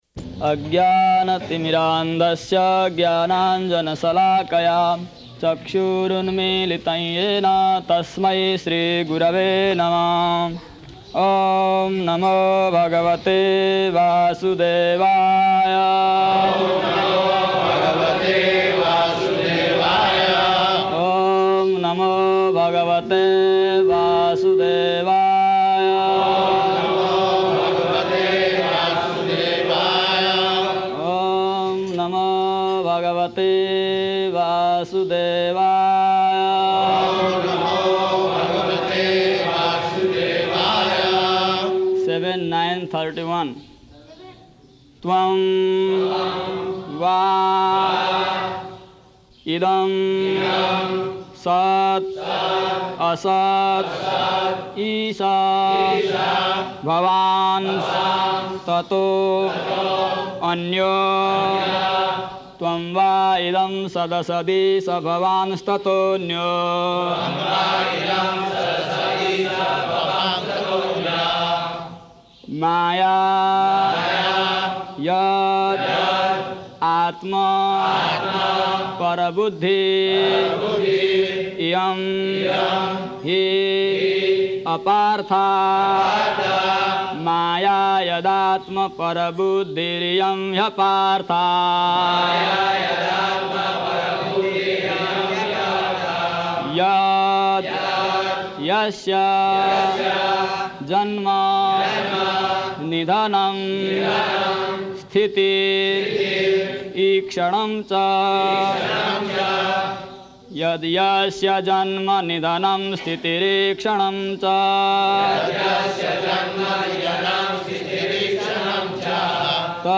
Location: Māyāpur
[chants synonyms; devotees respond]